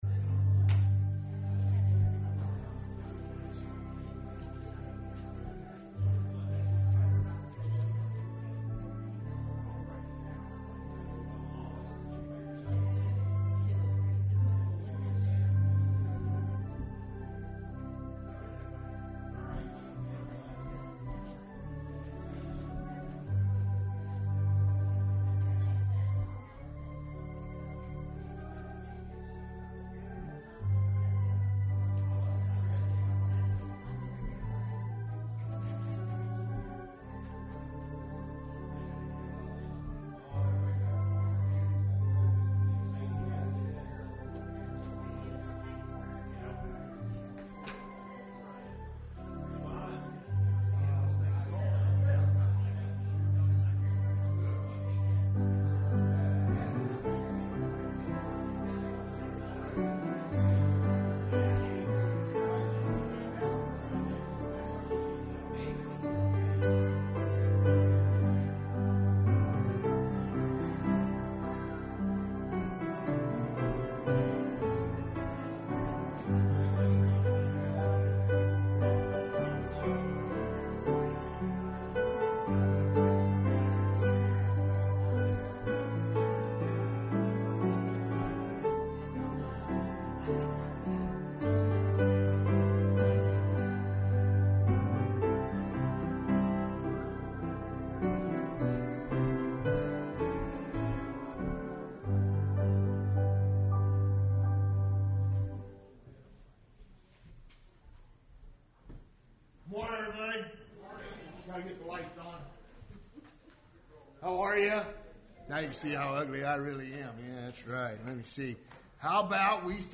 Service Type: Sunday Morning Service « The Gospel According to Isaiah 53 – Part 1 Leftovers from the Fridge!